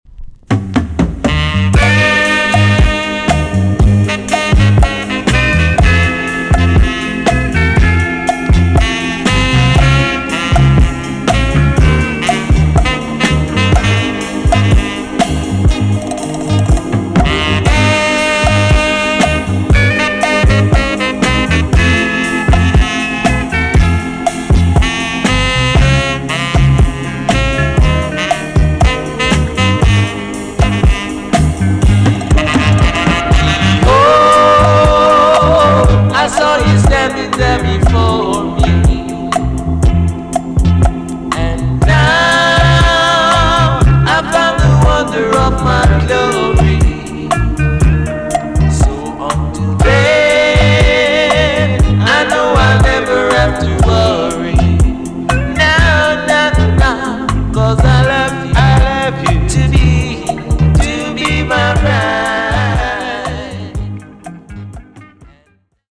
TOP >LP >VINTAGE , OLDIES , REGGAE
A.SIDE EX- 音はキレイです。